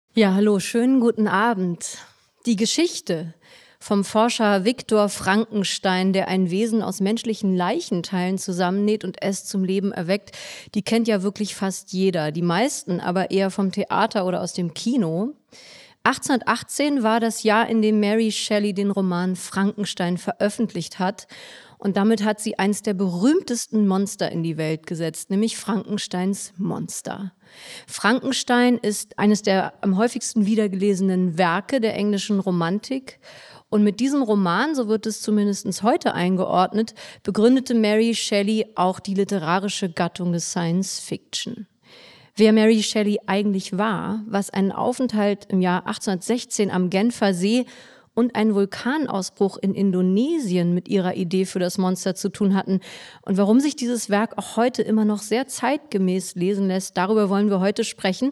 Mitarbeit Interviewte Person: Ulrike Draesner, John von Düffel